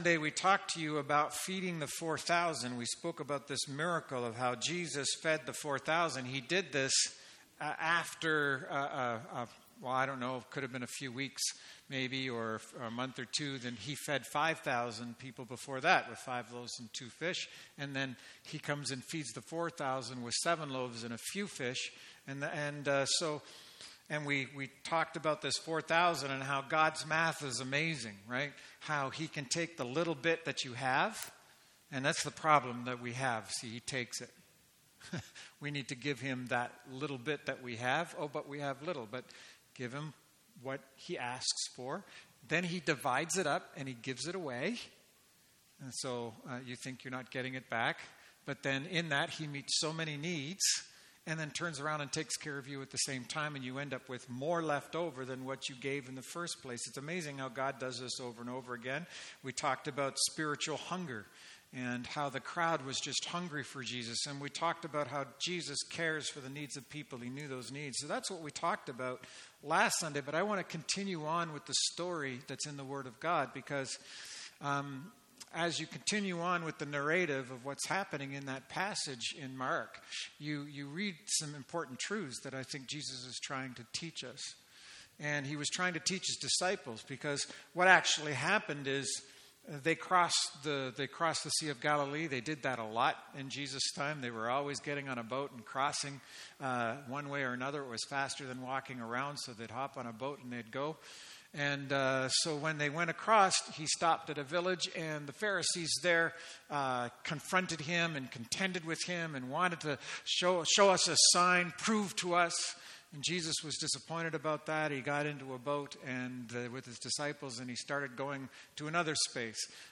Sermons | Kingston Gospel Temple